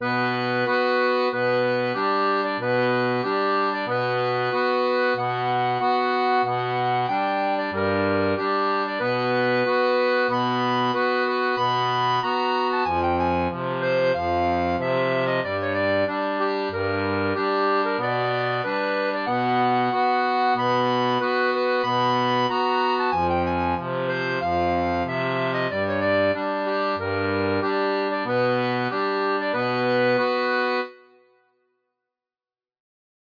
• Une tablature transposée pour diato à 3 rangs
Type d'accordéon
Jazz